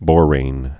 (bôrān)